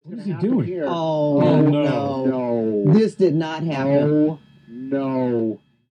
Cheer crowd
Cheer consertcrowd Crowd hall people sound effect free sound royalty free Memes